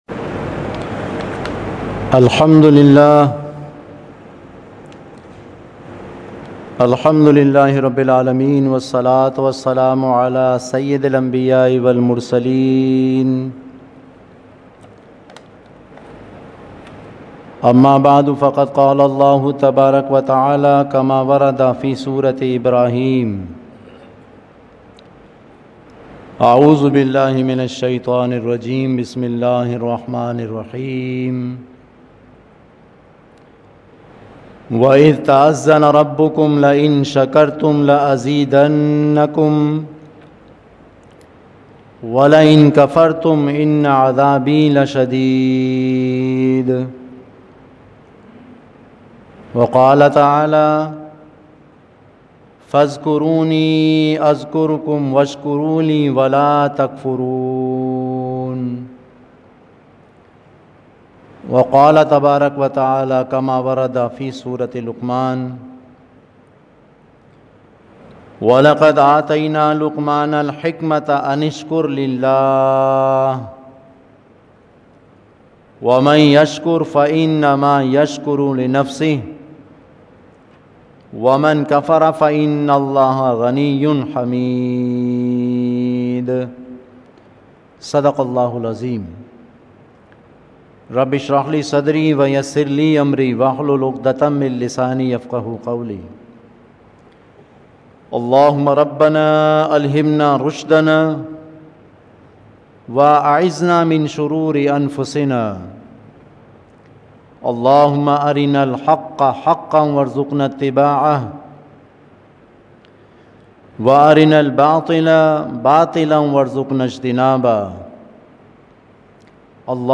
Khutbat-e-Jummah (Friday Sermons)